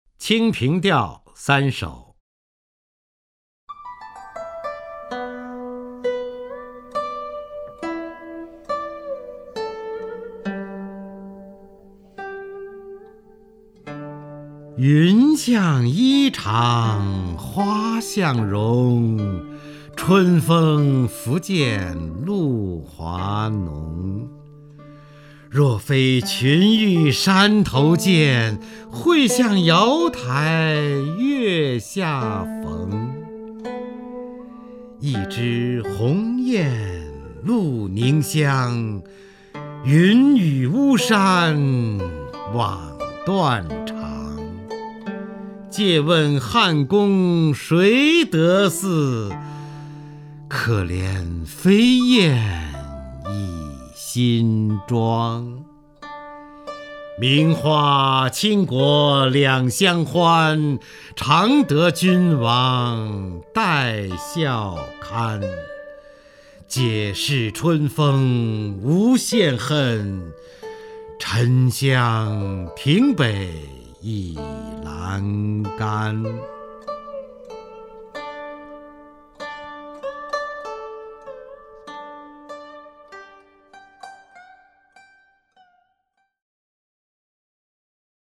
首页 视听 名家朗诵欣赏 方明
方明朗诵：《清平调词/清平调三首》(（唐）李白)